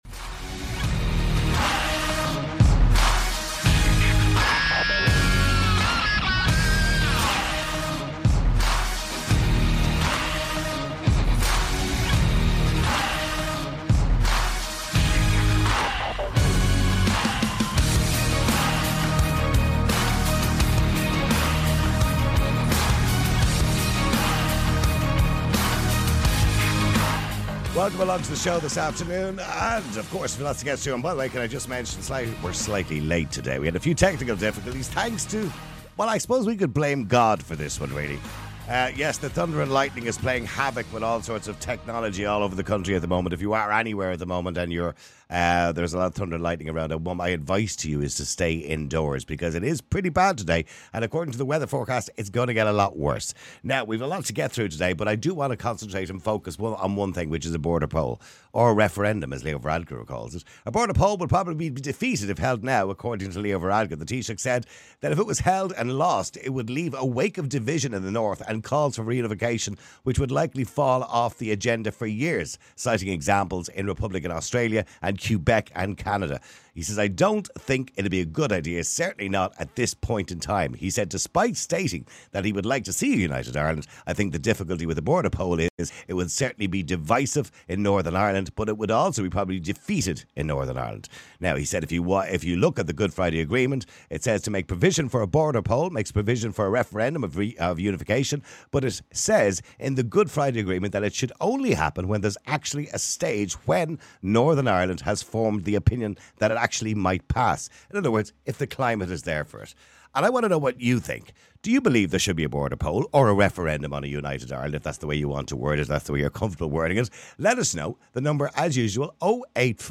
The majority of callers express reservations about the economic feasibility of a united Ireland at present, highlighting the need for careful financial planning before taking such a significant step.